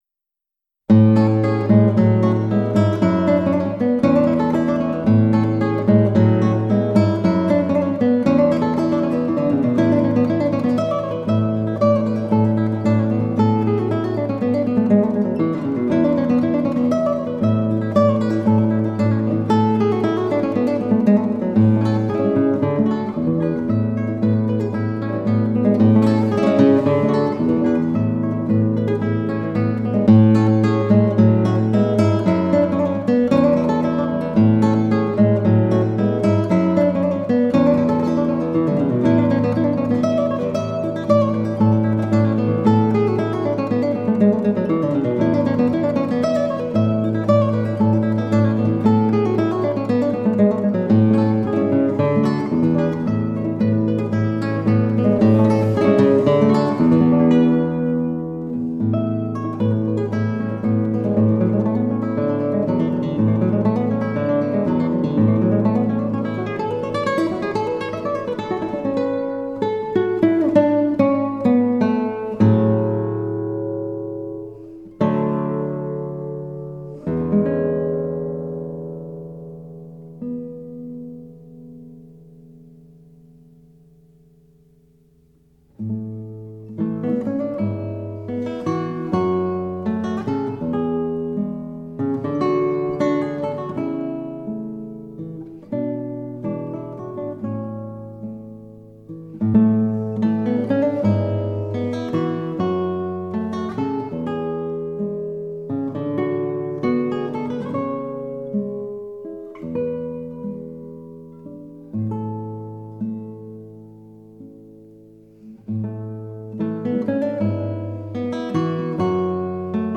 guitar.